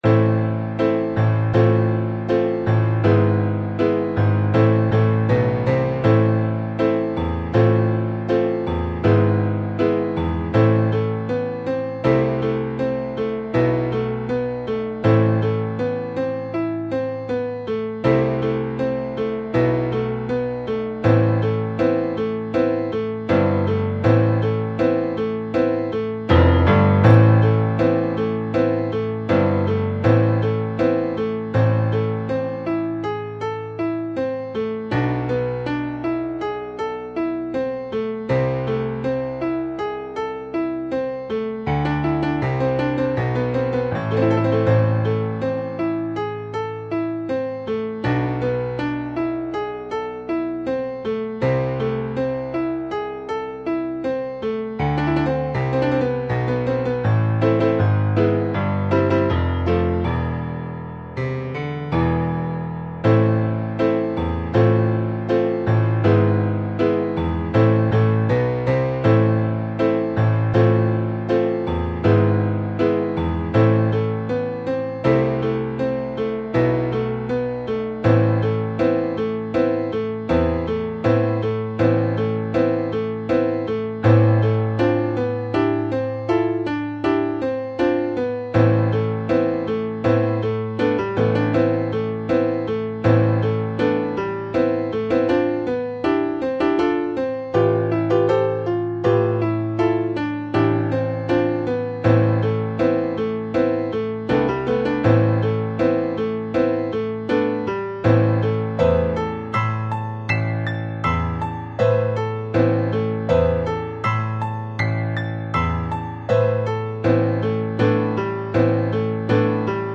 The following pieces consist of MIDI and/or MP3 files for the piano.
It was hard to get the midi to sound correct (I entered it by hand) but my live (MP3) recordings have mistakes - so you have a choice of accurate and uninspired, or inaccurate but at least not flat.